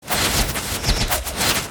many-arrows.wav